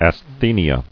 [as·the·ni·a]